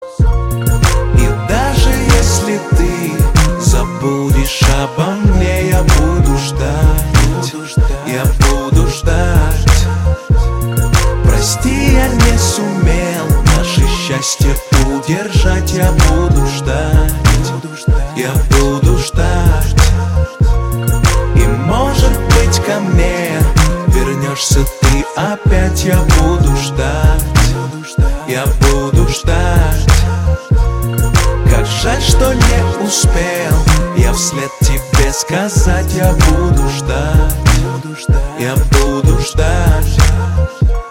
Главная » рингтоны на телефон » Рэп, Хип-Хоп, R'n'B